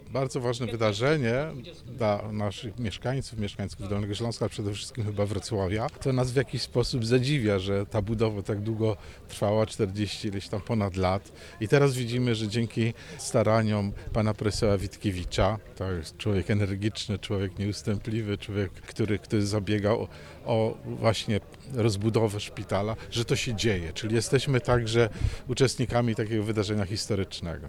– Jesteśmy uczestnikami wydarzenia historycznego – tak podsumował uroczystość wmurowania kamienia węgielnego abp Józef Kupny, metropolita wrocławski, który dokonał również poświęcenia budowy.